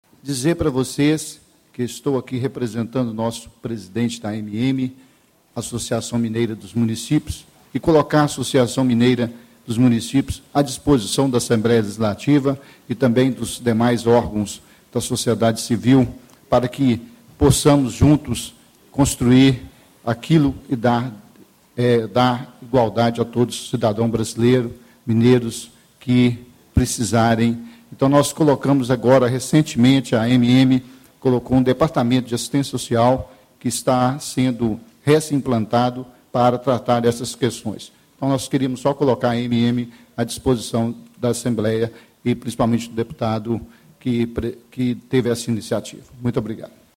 José Antônio Miranda, Prefeito de Japaraíba e Diretor da Região Centro-Oeste da Associação
Ciclo de Debates Estratégias para Superação da PobrezaMineira de Municípios
Discursos e Palestras